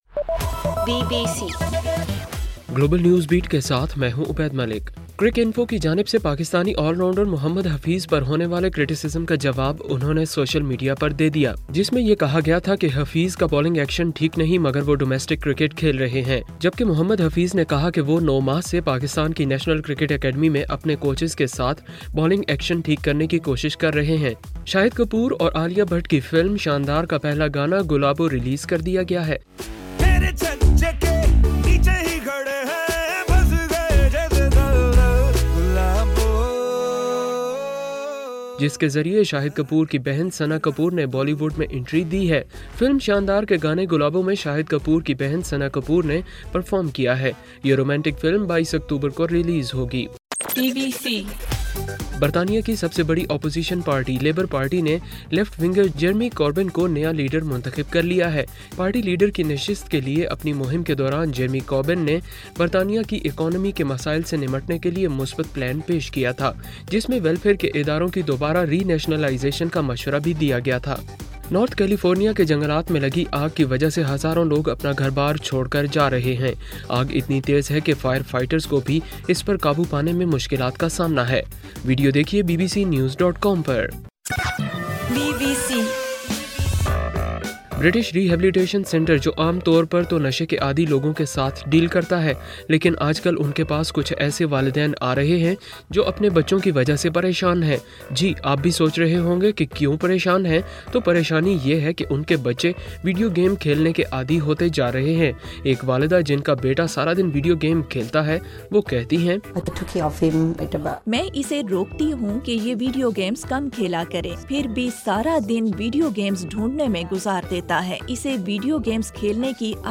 ستمبر 12: رات 9 بجے کا گلوبل نیوز بیٹ بُلیٹن